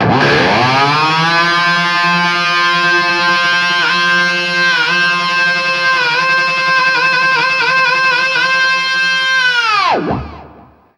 DIVEBOMB 4-R.wav